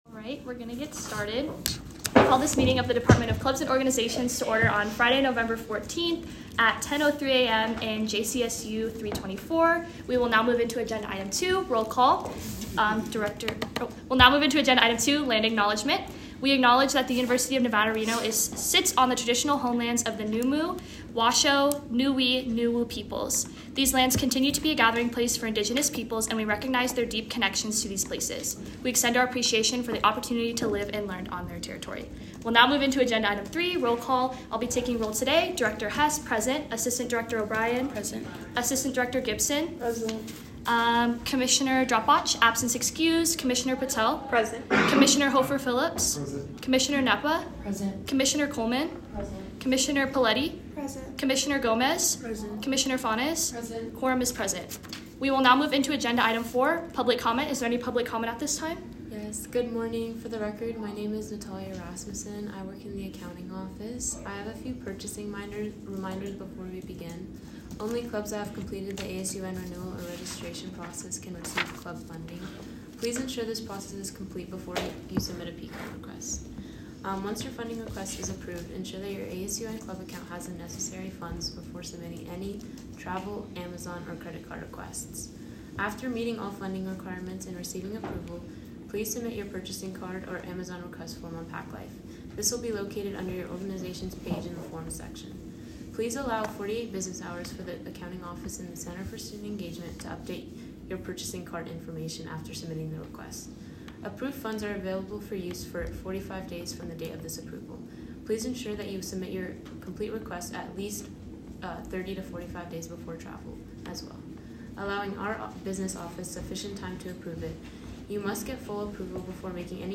Location : Rita Laden Senate Chambers - located on the third floor of the JCSU
Audio Minutes